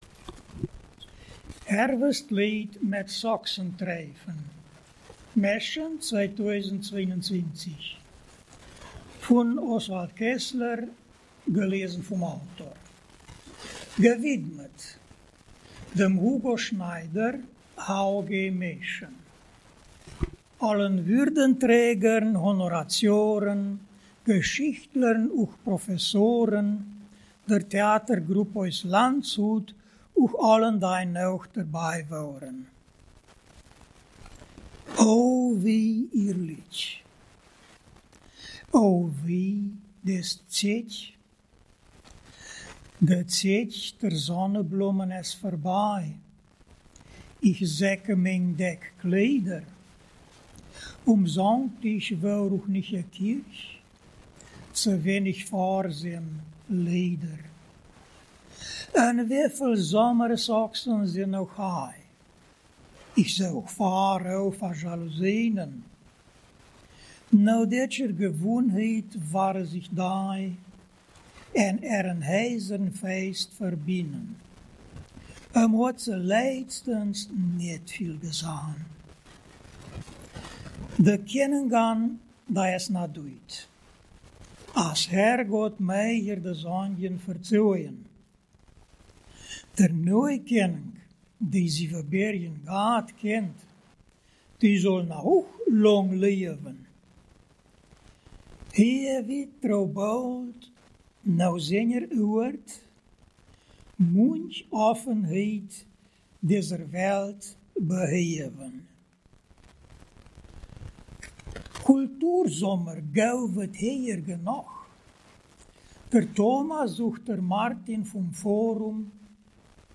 Ortsmundart: Kerz